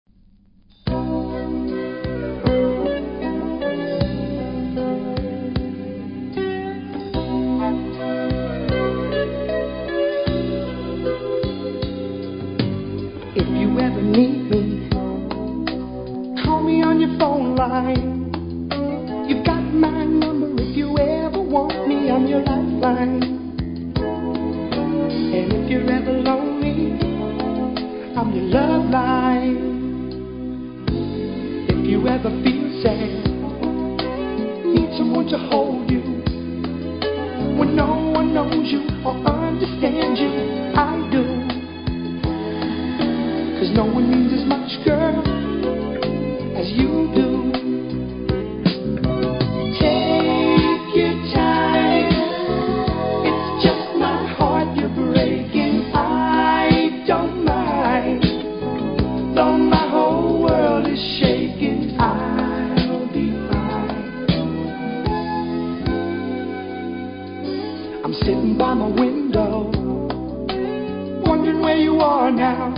「私は君のライフラインです、私が必要な時にはいつでも連絡下さい」とのラブソング♪